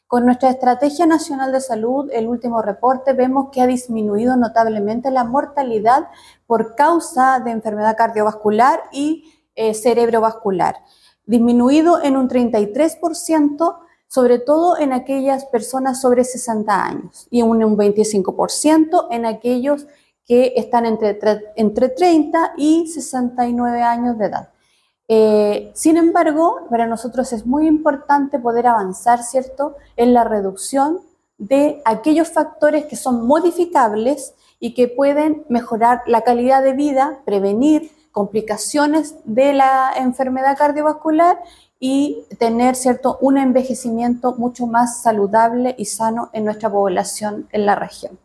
La seremi de Salud, Karin Solís, destacó la importancia de este tipo de instancias para fortalecer la red de salud en la región, fortaleciendo los equipos de salud, tanto de la atención primaria como secundaria, para disminuir notablemente los índices y los indicadores de la carga de morbimortalidad que tiene nuestra región y el país.